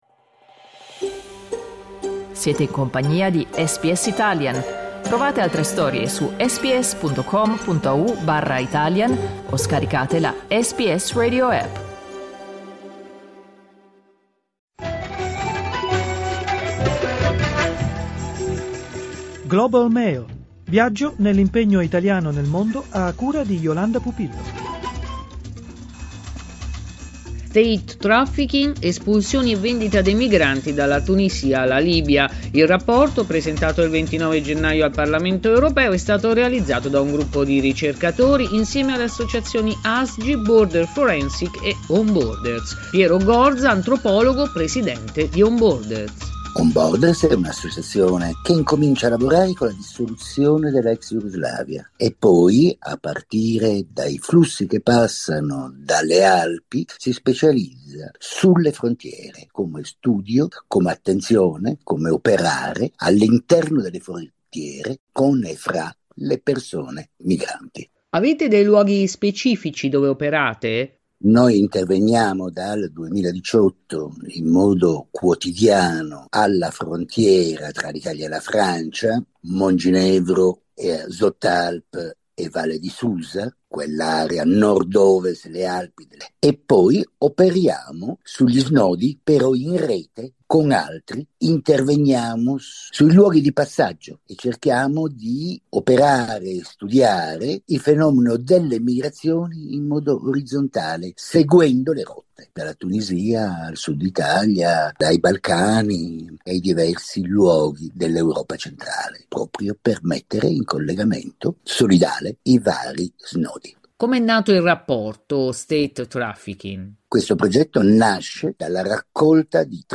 Clicca sul tasto "play" in alto per ascoltare l'intervista La Tunisia è diventata un punto di transito fondamentale per i migranti e i richiedenti asilo provenienti dall'Africa subsahariana che hanno l'obiettivo di raggiungere l'Europa.